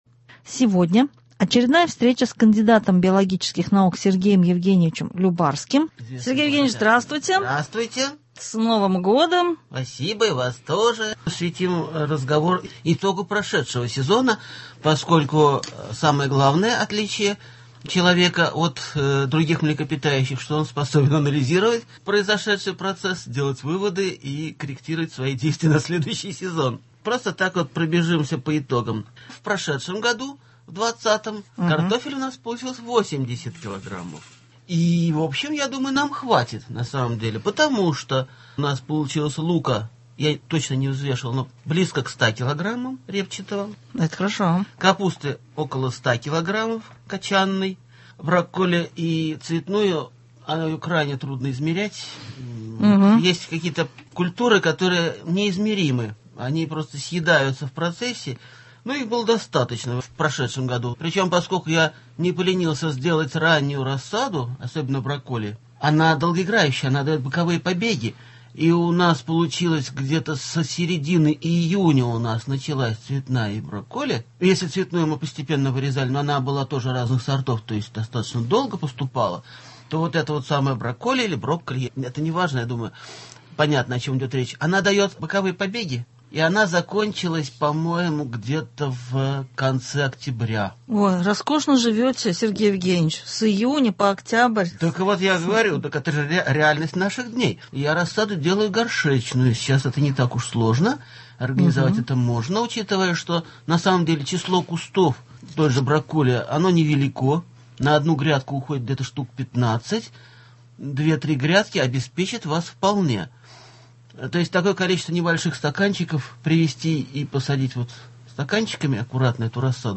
Гость студии — кандидат биологических наук